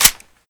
boltforward.wav